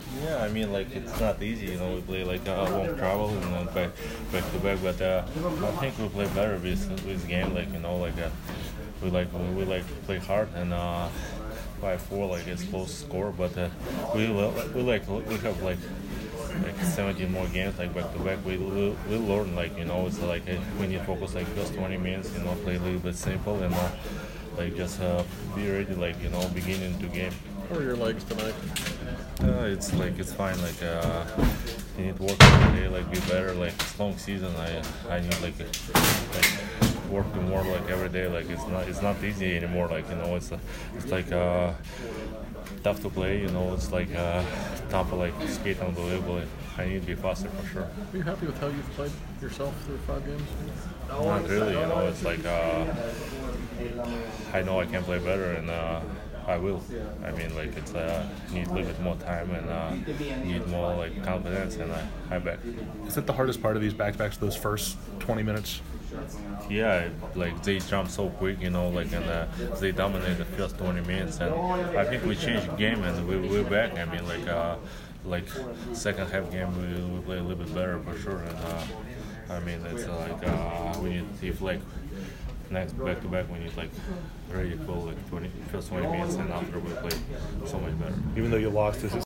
Evgeni Malkin Post Game 10/12